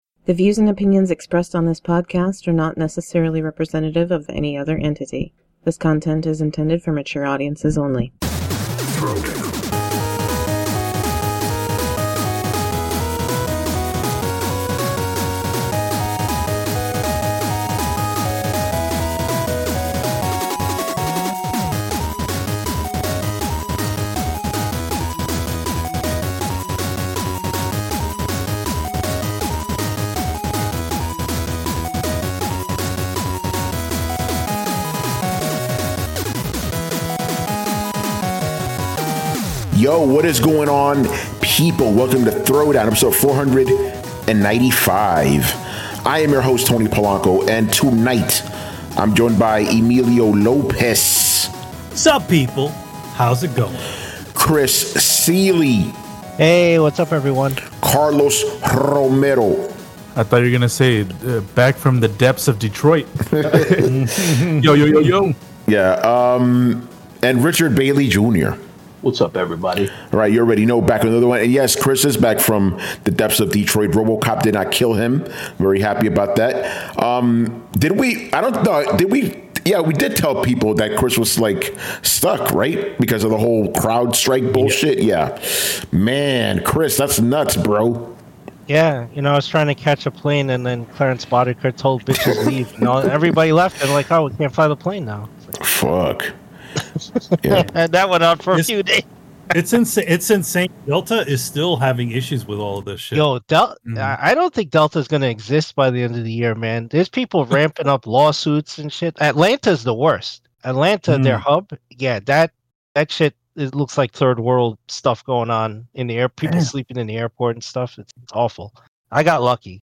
intro and outro music